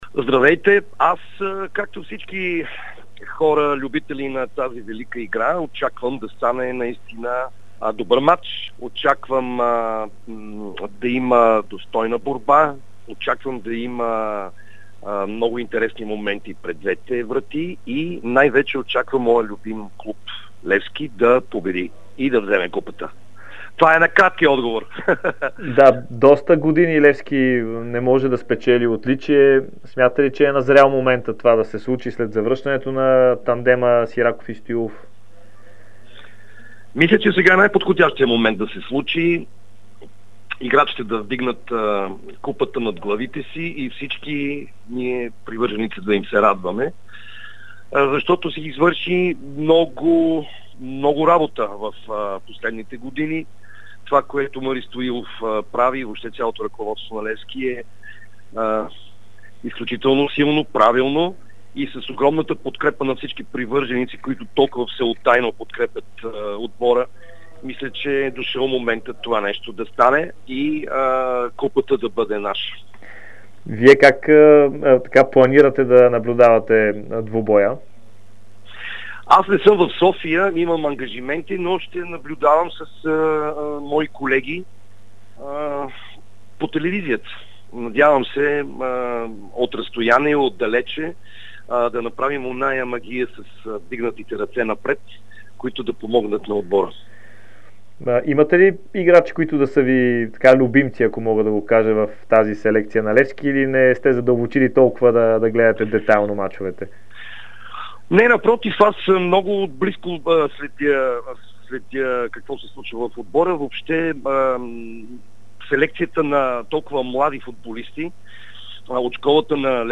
Известният актьор Камен Воденичаров даде ексклузивно интервю пред Дарик радио преди големия финал за Купата на България между ЦСКА и Левски.